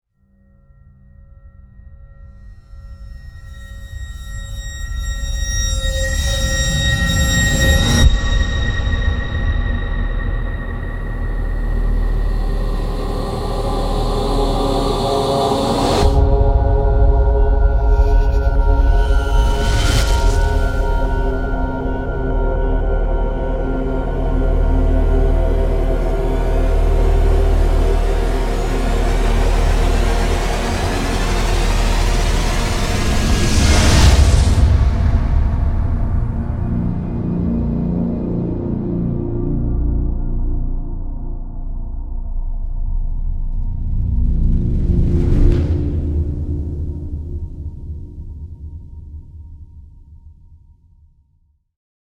令人毛骨悚然的悬疑乐器组，为银幕配乐而设的专属采样。
惊心动魄的悬念感
这是一种用于扣人心弦的电影配乐的强大动作乐器组，具有独家声音
声音类别: 电影配乐